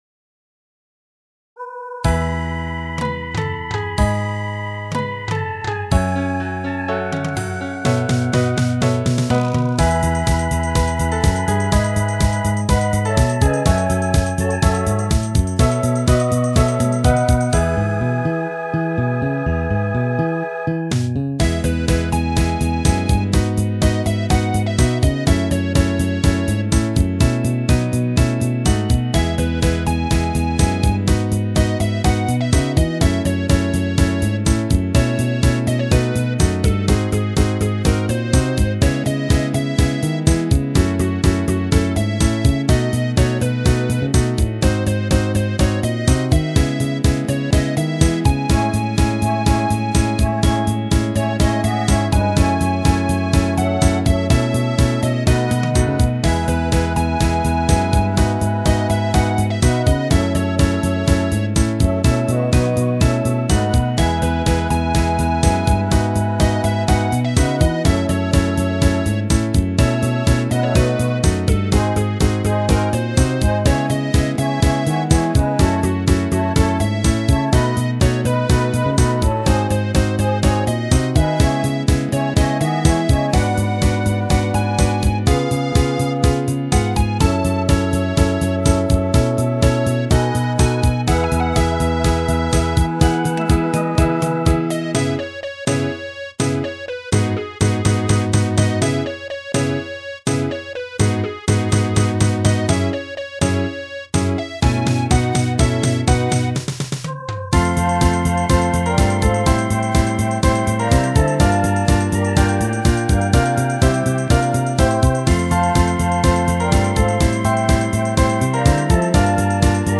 達郎調のボーカル帯がメインの曲なのでバンド帯はシンプルに。